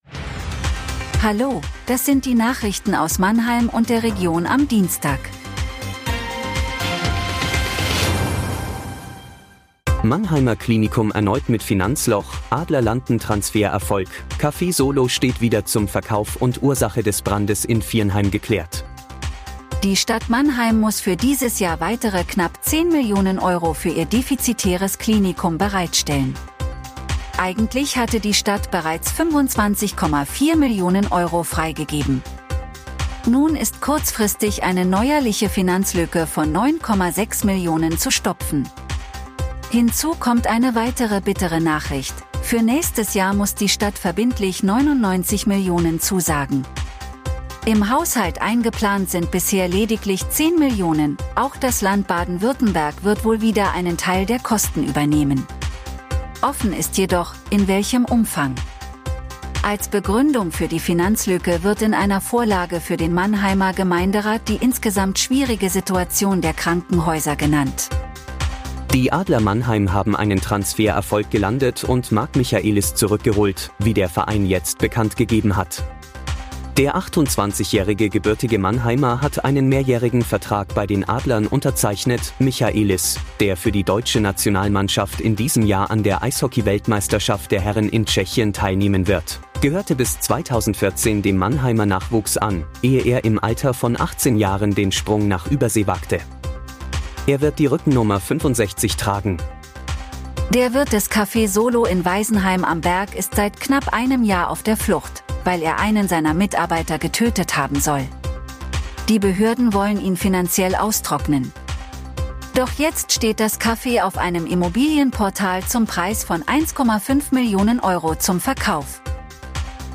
Der Nachrichten-Podcast des MANNHEIMER MORGEN
Nachrichten